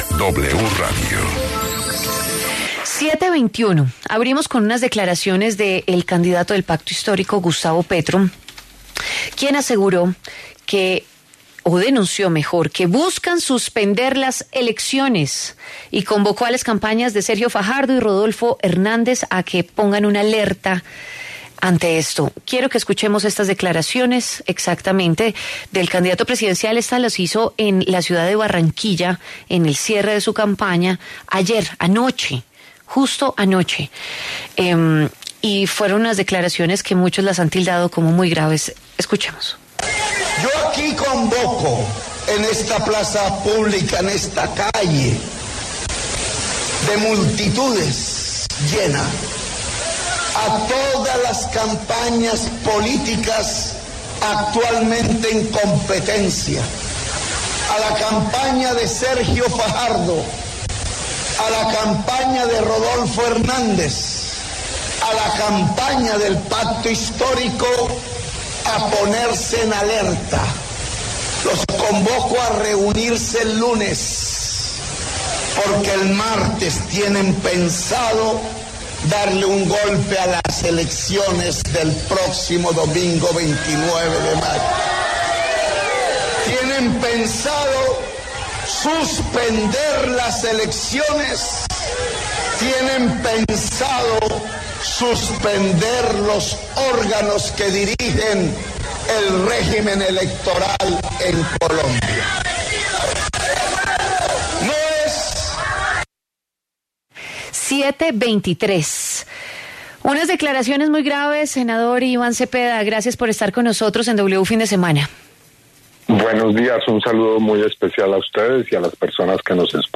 Debate: ¿están en riesgo las elecciones presidenciales tras denuncia de Gustavo Petro?